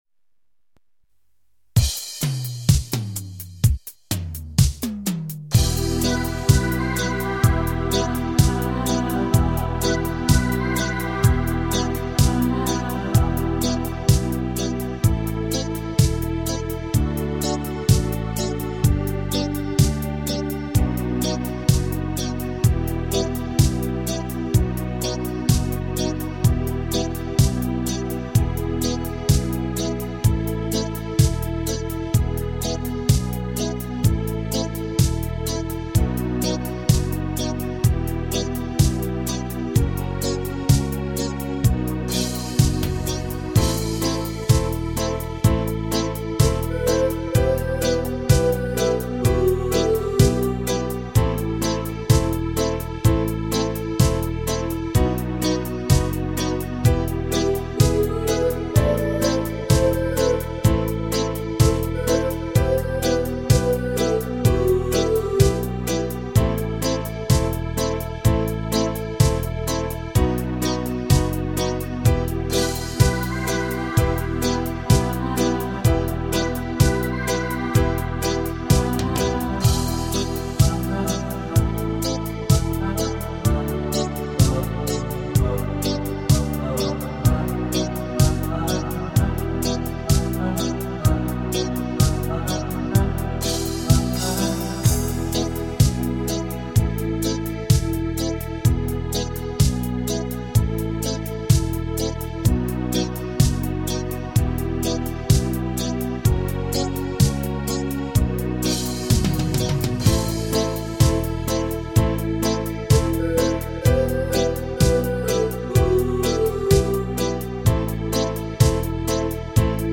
Вот в качестве компенсации - минусовка :)